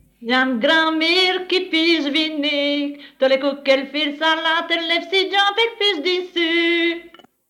Type : chanson narrative ou de divertissement | Date : 15 septembre 1959
Mode d'expression : chant Lieu : Morville Collecteur(s